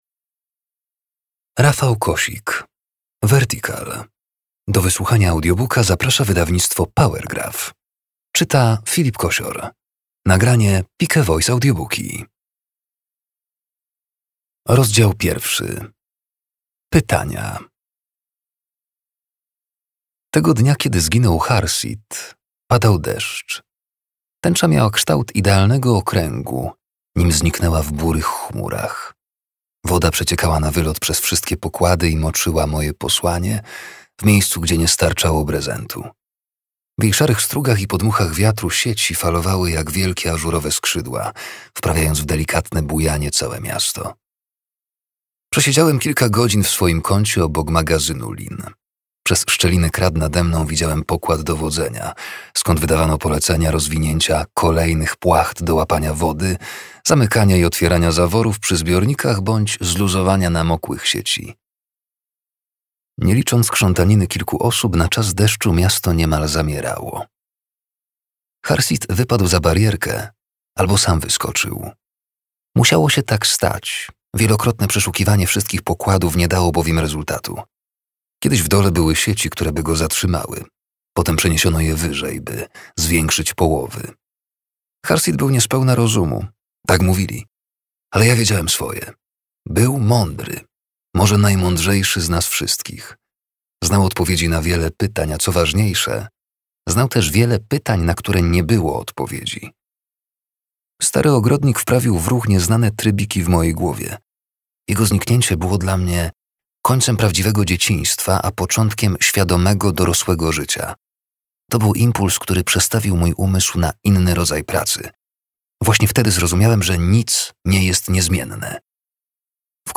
Vertical - Rafał Kosik - audiobook + książka